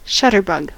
shutterbug: Wikimedia Commons US English Pronunciations
En-us-shutterbug.WAV